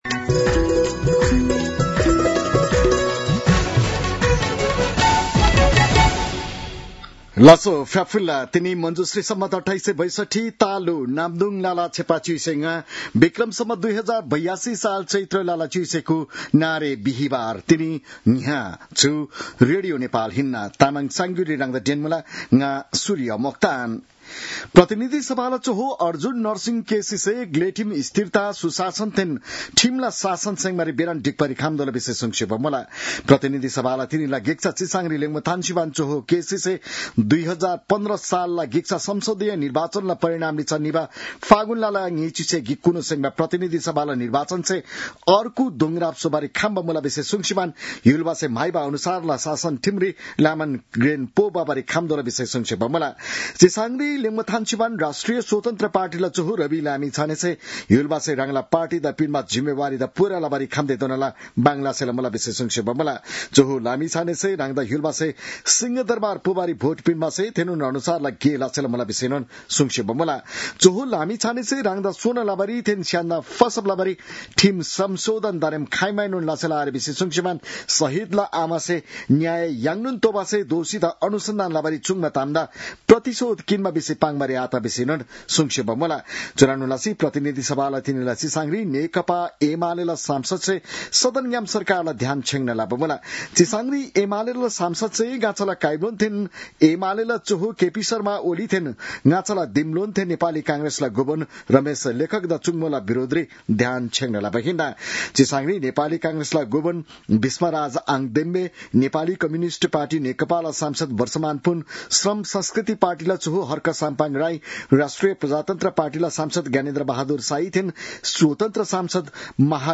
An online outlet of Nepal's national radio broadcaster
तामाङ भाषाको समाचार : १९ चैत , २०८२